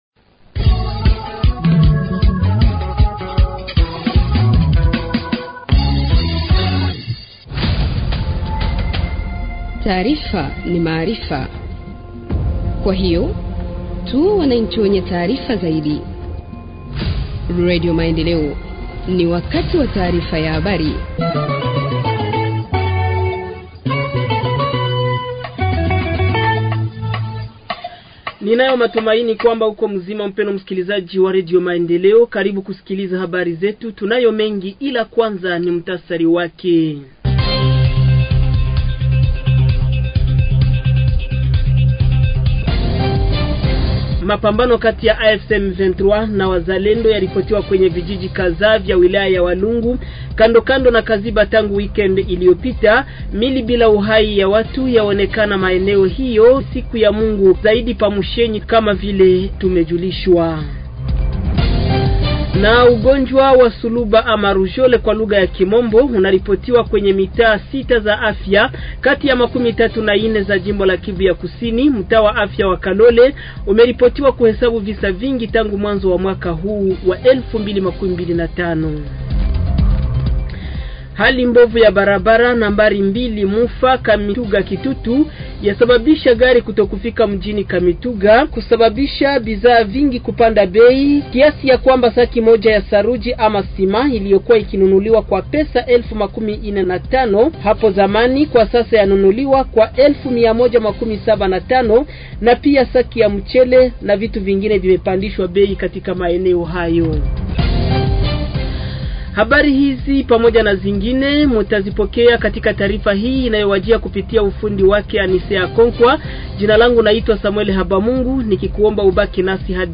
Journal en Swahili du 29 avril 2025 – Radio Maendeleo